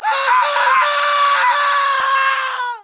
scream11.ogg